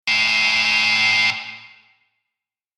Basketball Buzzer 4 Sound Effect Download | Gfx Sounds
Basketball-buzzer-4.mp3